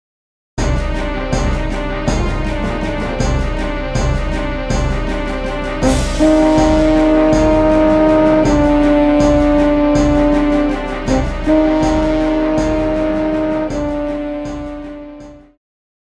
French Horn
Band
Instrumental
World Music,Electronic Music
Only backing